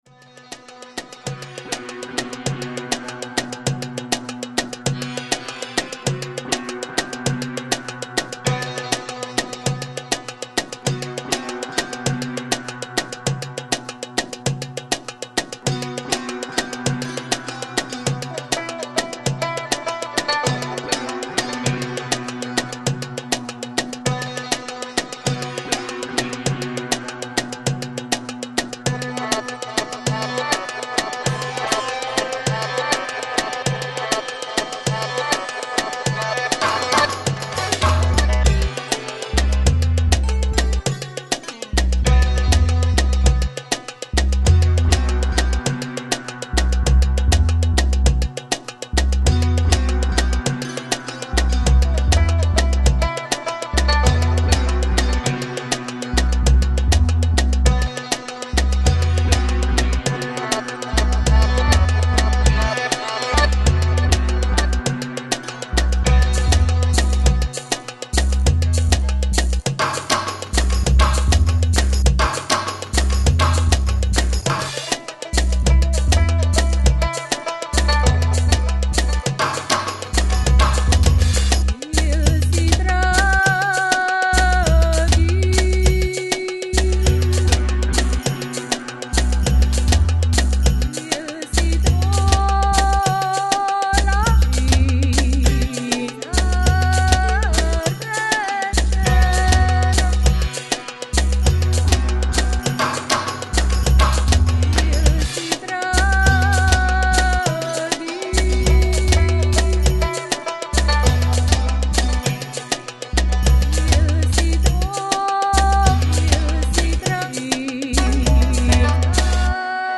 FLAC Жанр: World, Ethnic, Oriental Folk Издание